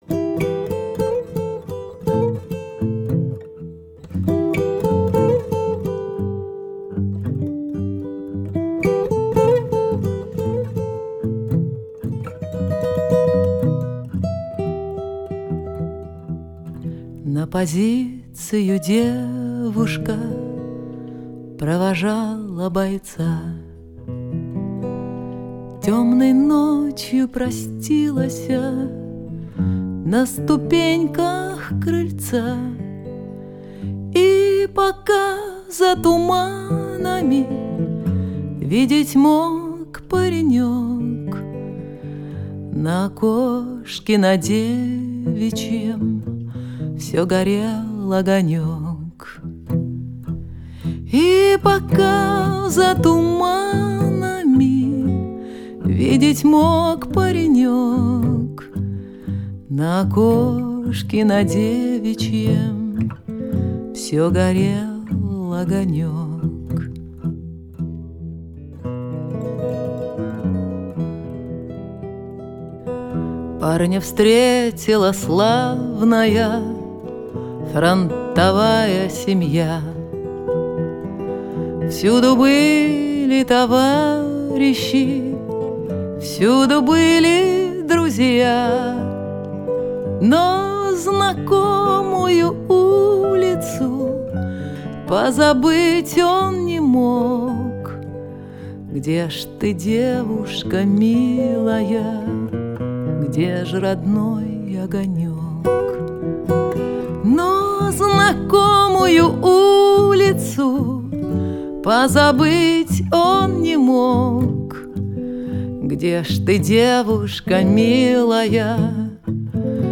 Original folk, traditional gospel, Russian and Chilean folk.
WWII Russian folk song